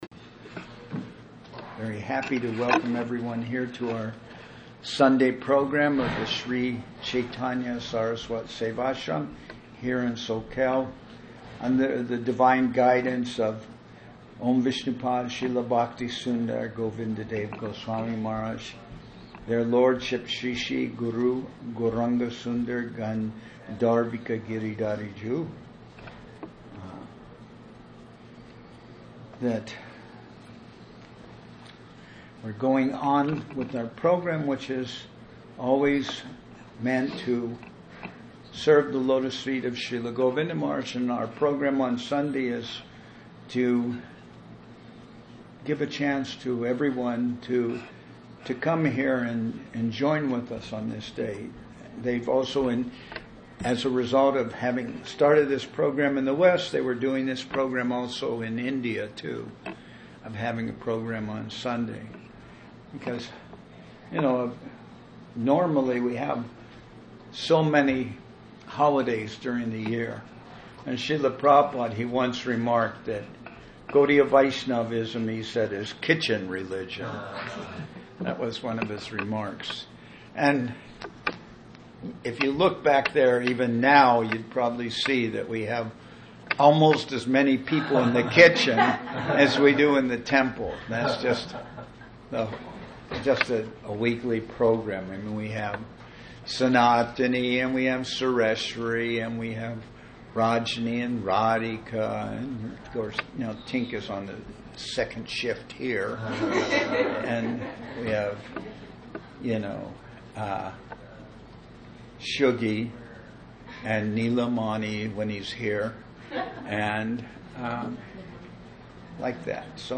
Place: Sri Chaitanya Saraswat Seva Ashram Soquel